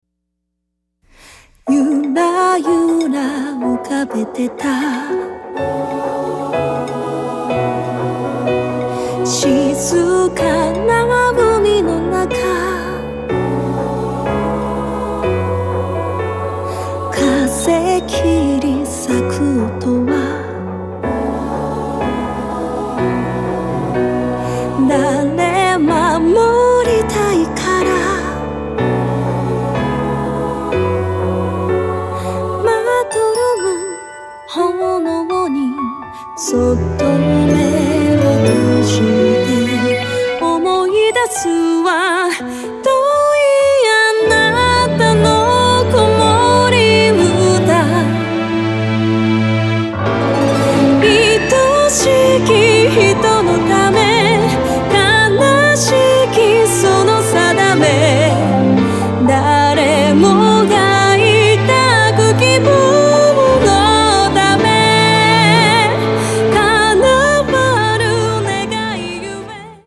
儚さポップ＆ロック全開！
Guitar
Bass
Piano & strings